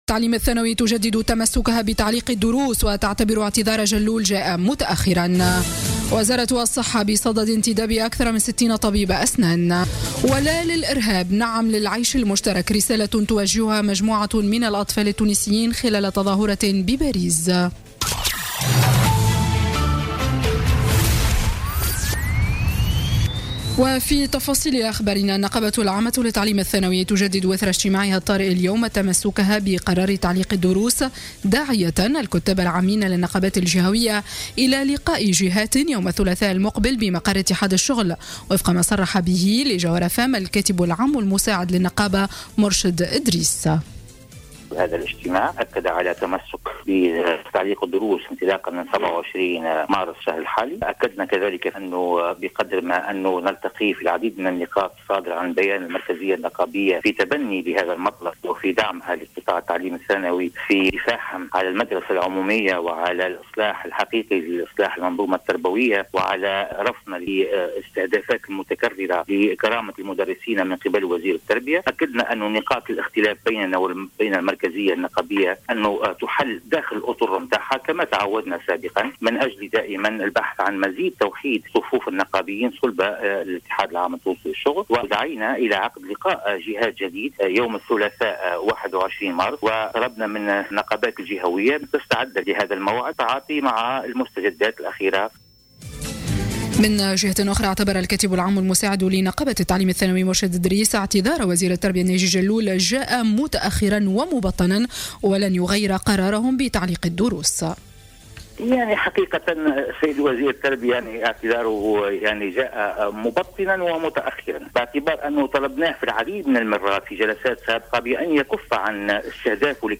Journal Info 19h00 du dimanche 19 Mars 2017